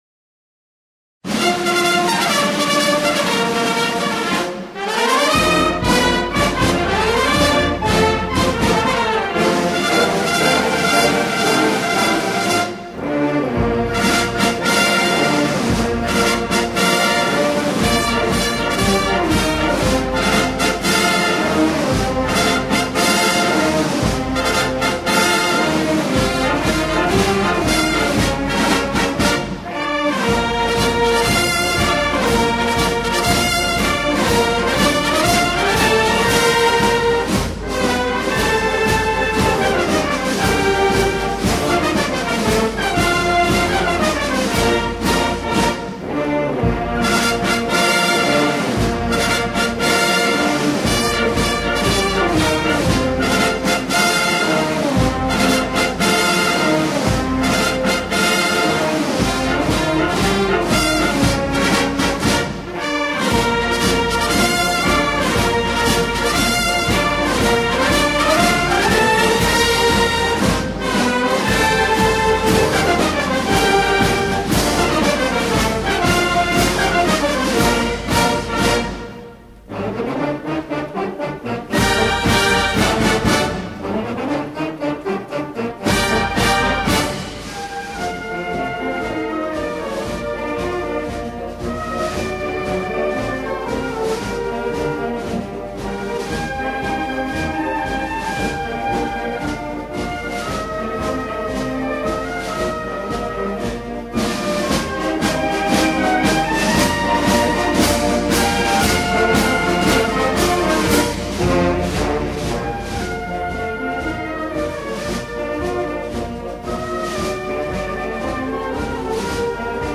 MHpr5QO0MKR_Marcha-de-San-Lorenzo-Instrumental-datos-históricos-.m4a